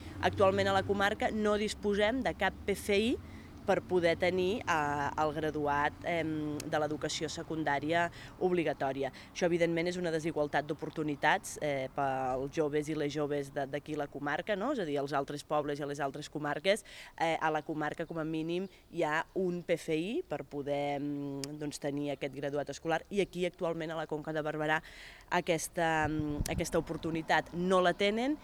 ÀUDIO: La regidora d’Educació de l’Espluga de Francolí, Anna Garcia, explica la situació de la comarca